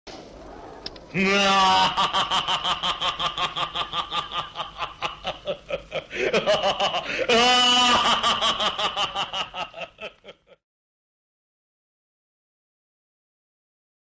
Play Hayati Gülme Efekti - SoundBoardGuy
Play, download and share hayati gülme efekti original sound button!!!!
hayati-hamzaoglu-nihahahaha-super-gulme-efekti.mp3